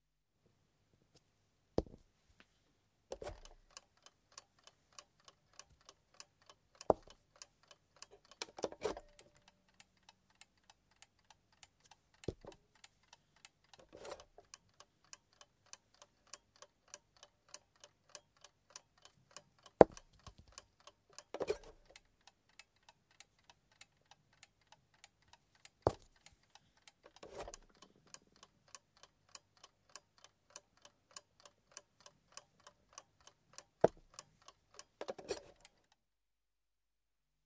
Шум шахматных часов в процессе игры